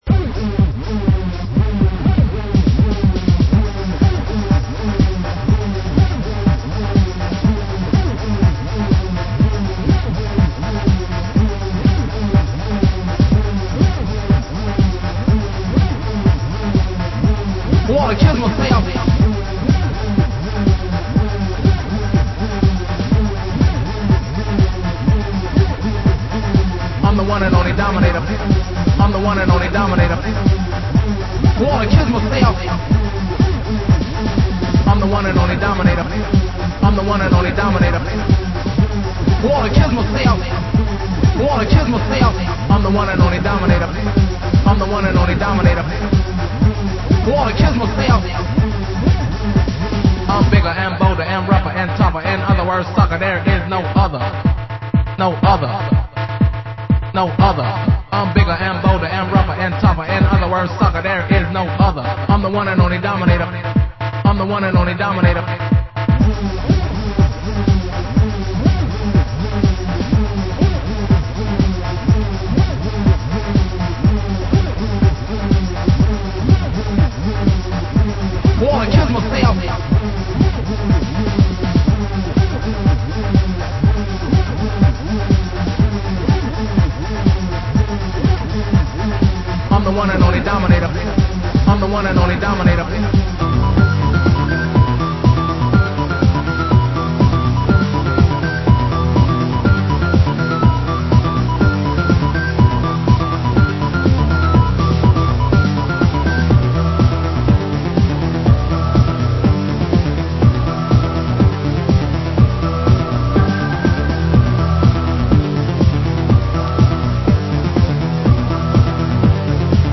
Genre: Euro Techno